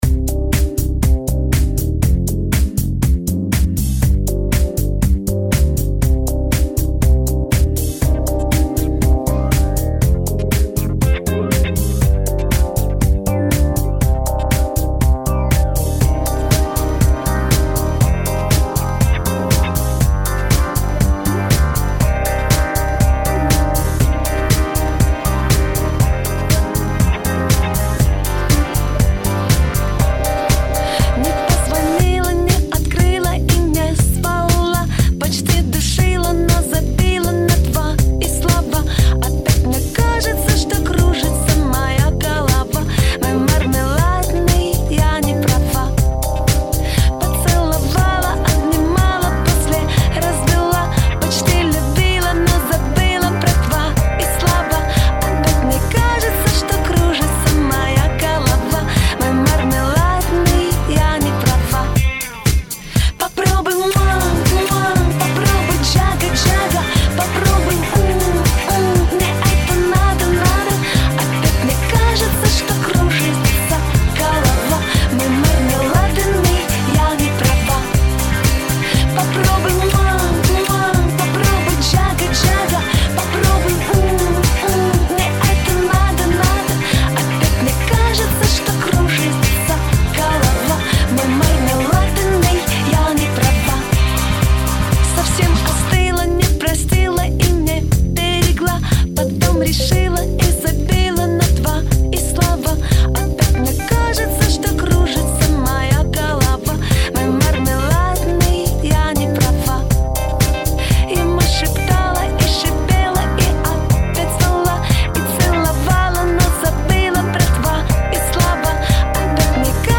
Писк идет из глубины глубин, из центра земли.